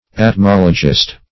Atmologist \At*mol"o*gist\, n. One who is versed in atmology.